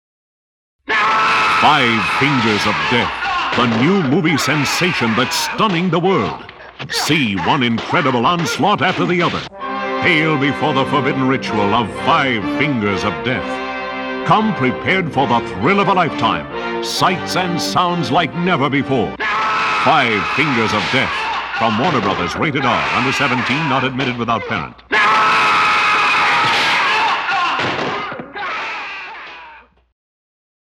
Radio Spots
So now, though, put on your gi or your Kung Fu uniform, assume your beginning stance, and listen to radio spots for the movie that started it all.